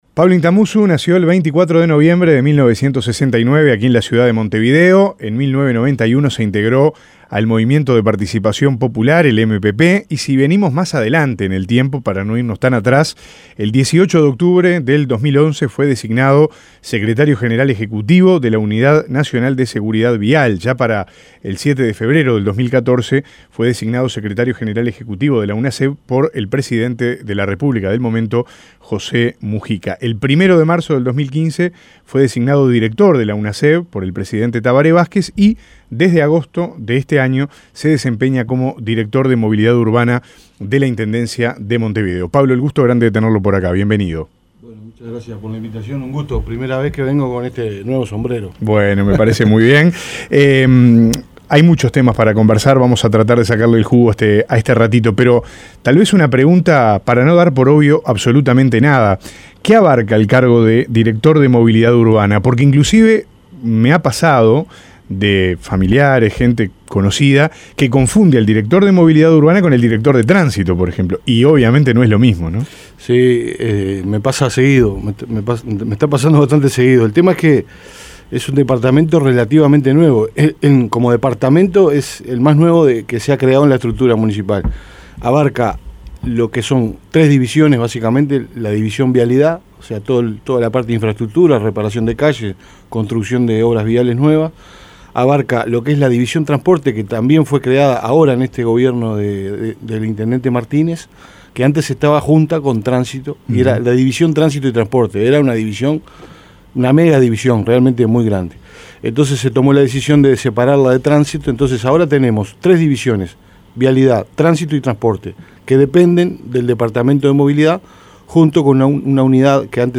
Escuche la entrevista en La Mañana